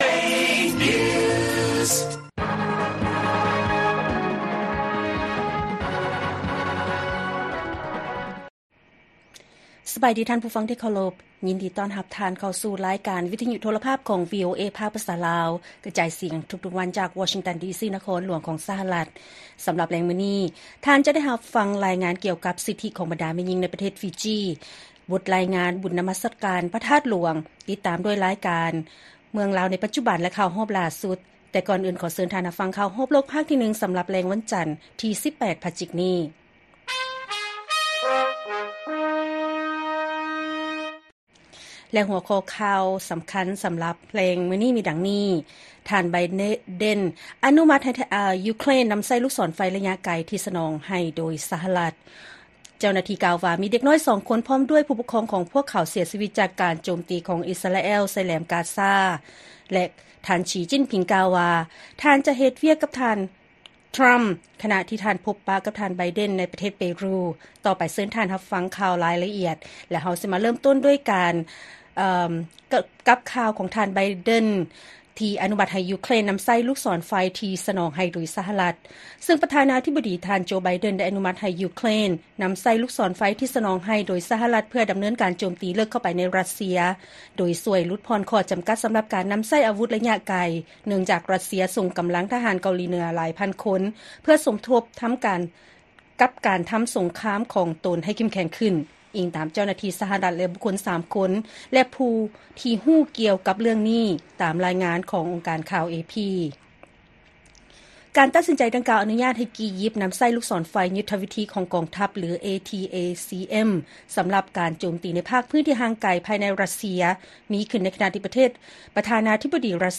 ລາຍການກະຈາຍສຽງຂອງວີໂອເອລາວ: ທ່ານ ໄບເດັນ ອະນຸມັດໃຫ້ ຢູເຄຣນ ນໍາໃຊ້ລູກສອນໄຟໄລຍະໄກ ທີ່ສະໜອງໃຫ້ໂດຍ ສະຫະລັດ